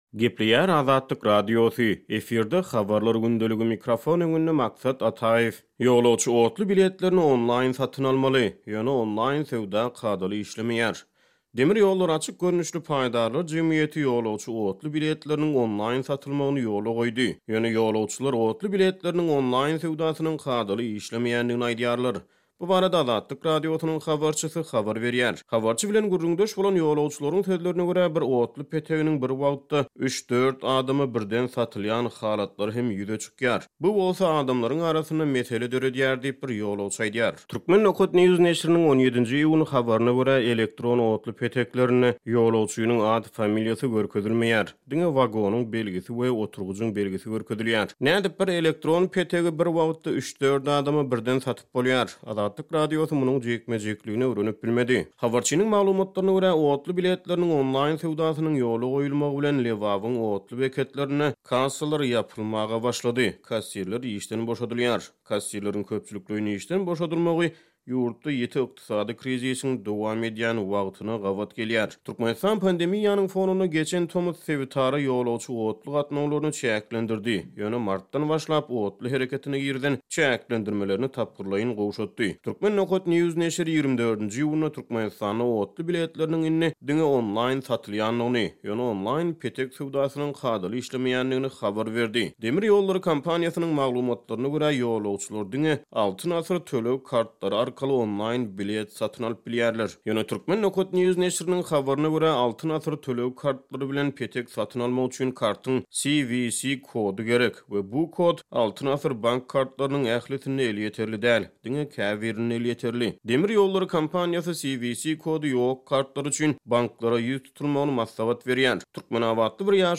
Ýöne ýolagçylar otly biletleriniň onlaýn söwdasynyň kadaly işlemeýändigini aýdýarlar. Bu barada Azatlyk Radiosynyň habarçysy habar berýär.